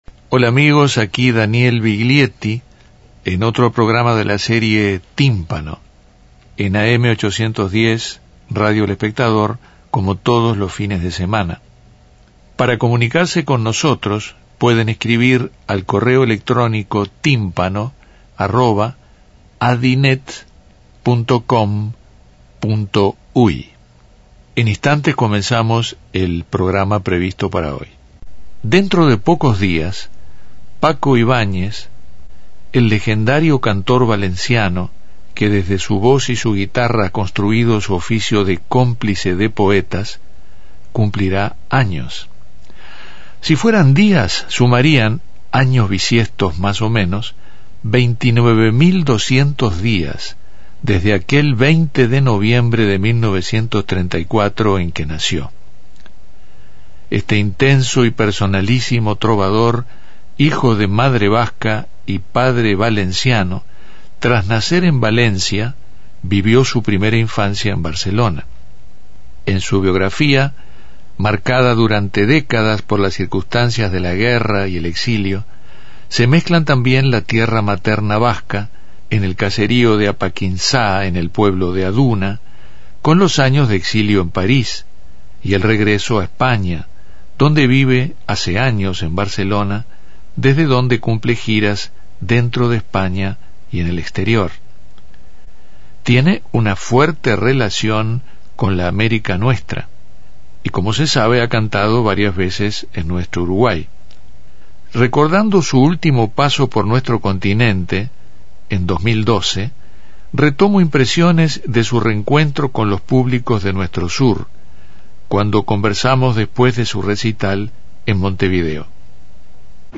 En las cercanías del 80° cumpleaños del popular músico valenciano, en Tímpano seleccionando pasajes de la entrevista que Daniel Viglietti le realizara cuando su última gira a nuestro sur.